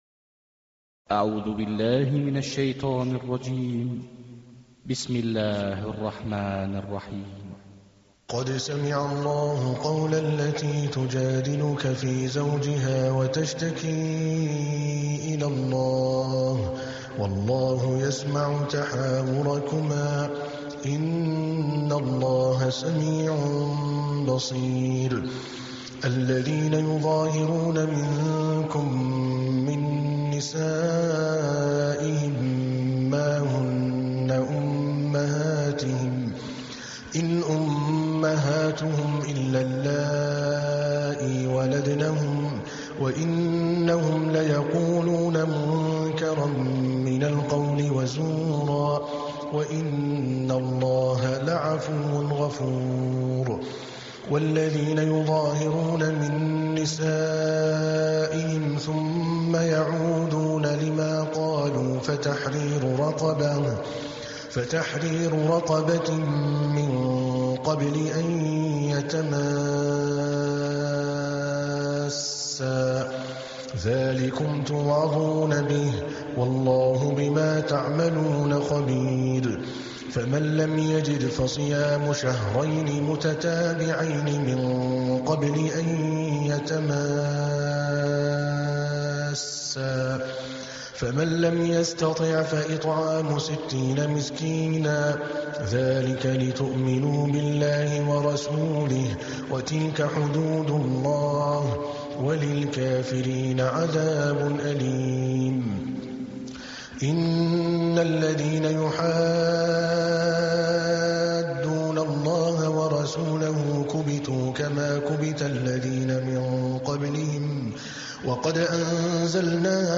تحميل : 58. سورة المجادلة / القارئ عادل الكلباني / القرآن الكريم / موقع يا حسين